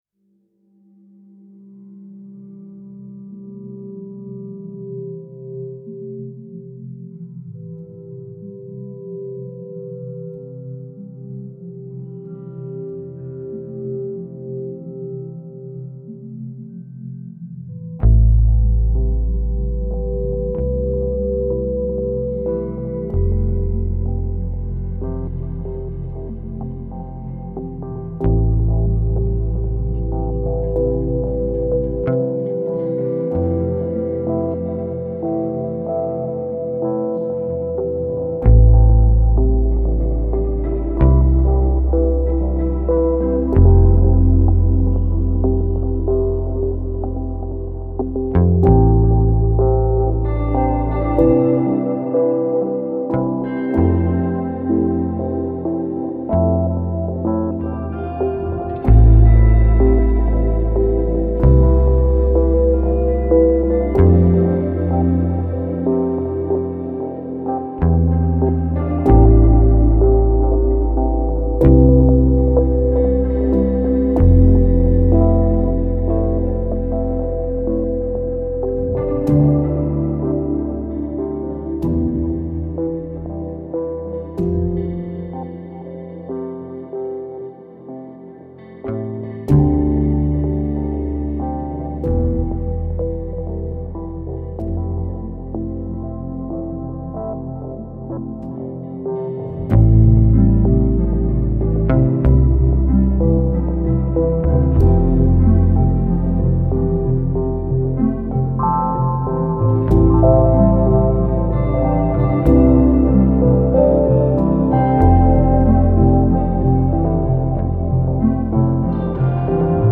Gentle synths and soft electronic textures.